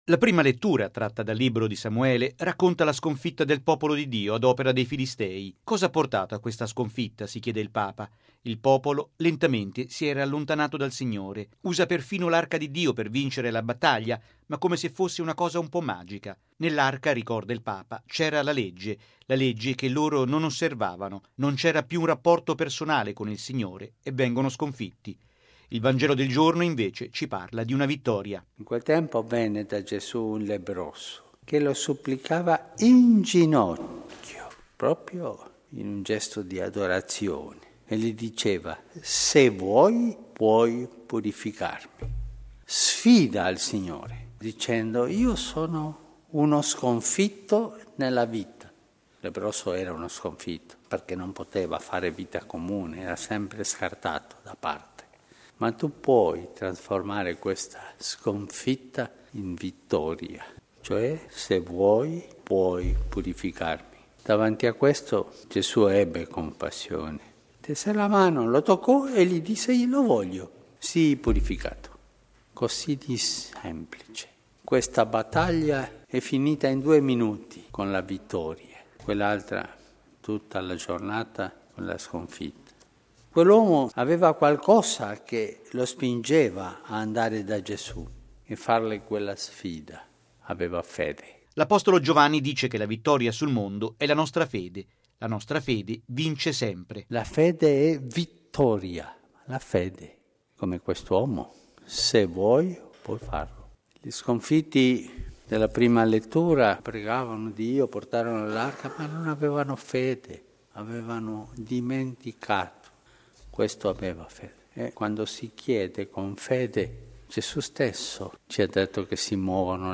La fede vince sempre, perché trasforma in vittoria anche la sconfitta, ma non è una cosa "magica", è un rapporto personale con Dio che non s'impara sui libri, è infatti un dono di Dio, un dono da chiedere: è questo, in sintesi, quanto ha detto il Papa nella Messa del mattino a Casa Santa Marta.